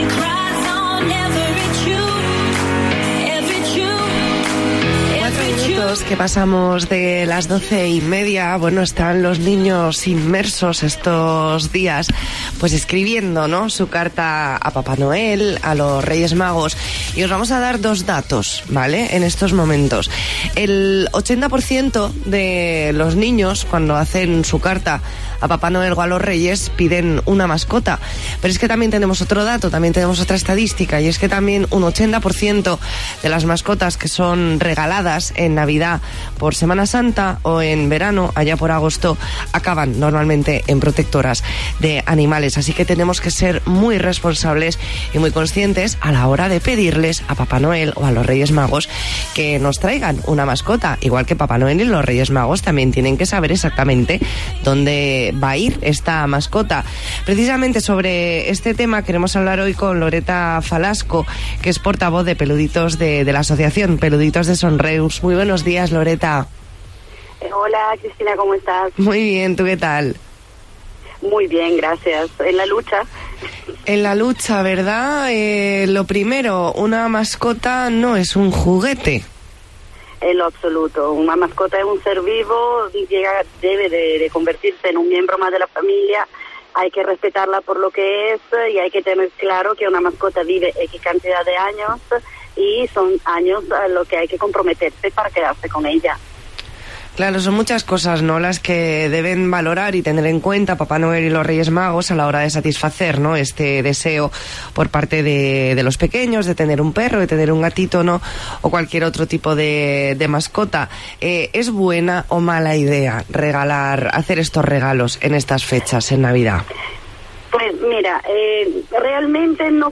Entrevista en La Mañana en COPE Más Mallorca, jueves 19 de diciembre de 2019.